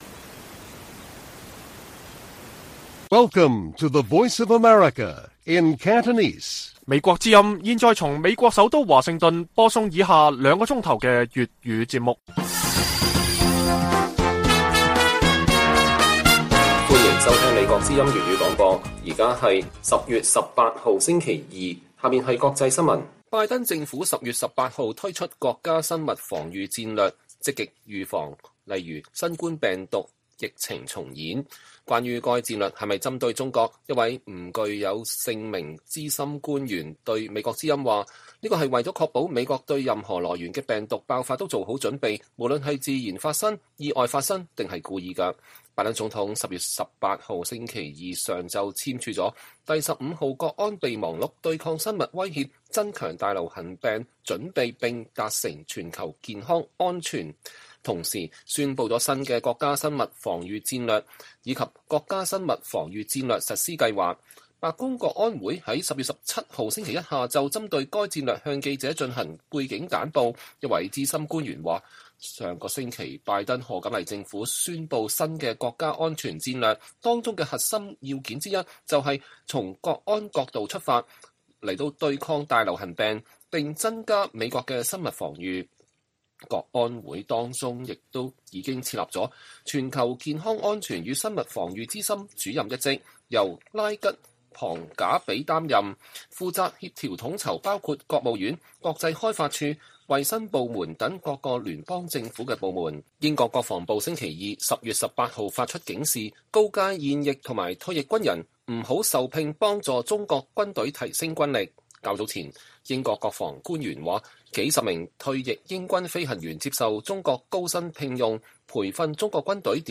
粵語新聞 晚上9-10點: 英國首相關注香港示威者被中國領事官員毆打事件